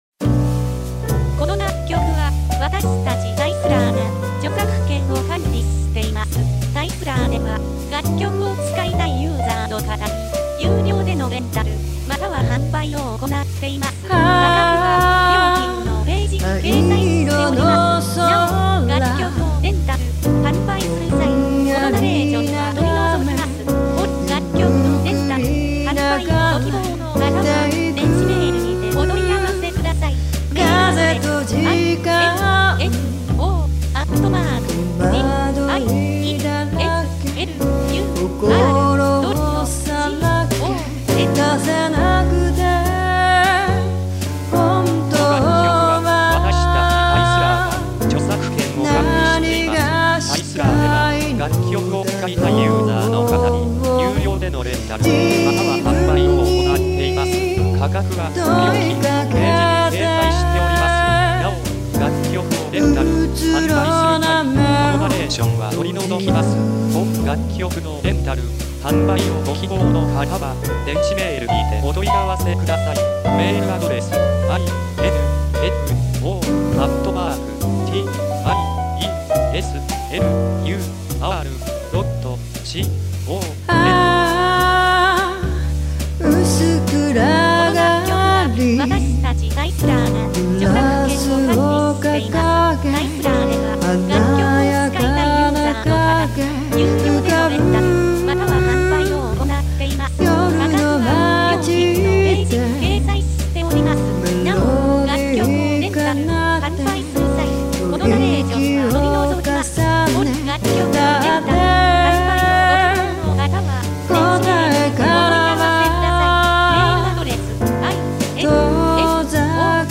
◆バラード系ボーカル曲